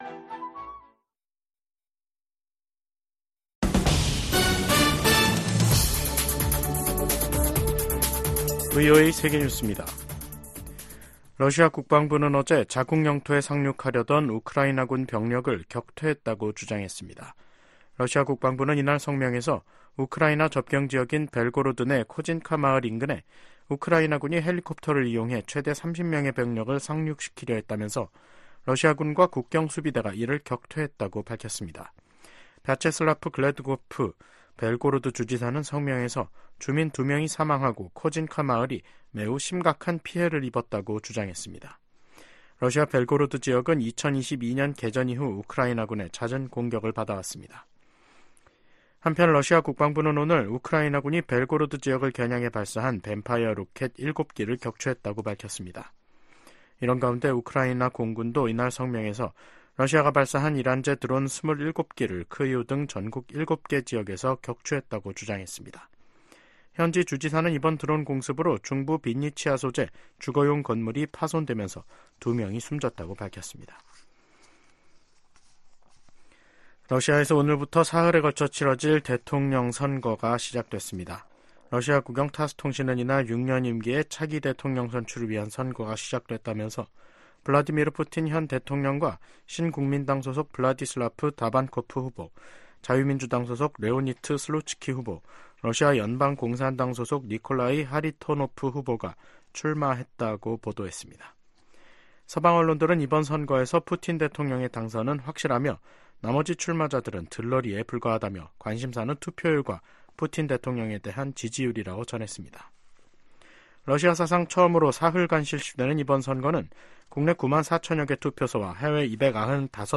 VOA 한국어 간판 뉴스 프로그램 '뉴스 투데이', 2024년 3월 15일 2부 방송입니다. 토니 블링컨 미 국무장관이 한국 주최 제3차 민주주의 정상회의 참석을 위해 서울을 방문합니다. 미 국방부는 한국의 우크라이나 포탄 지원 문제에 관해 우크라이나를 돕는 모든 동맹국을 지지한다는 원론적 입장을 밝혔습니다. 중국 내 탈북민 인권 보호를 위해 미국 정부가 적극적으로 나설 것을 촉구하는 결의안이 하원에서 발의됐습니다.